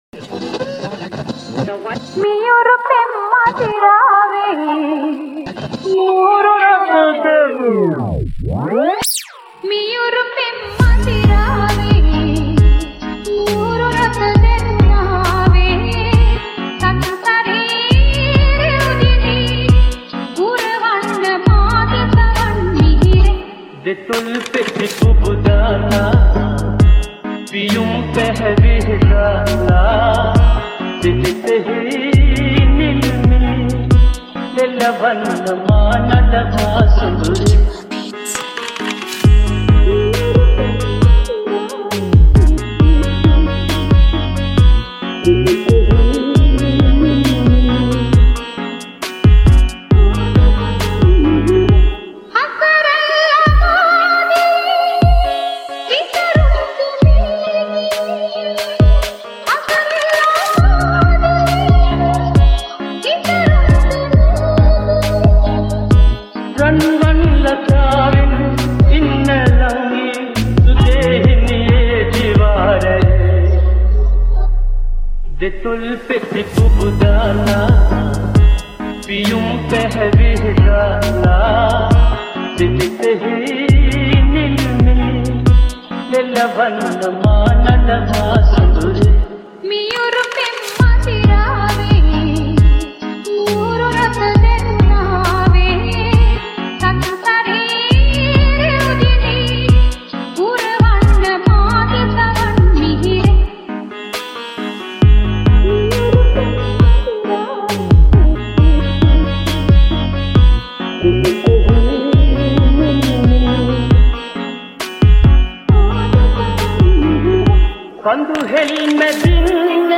Kawadi Baila Dance Mix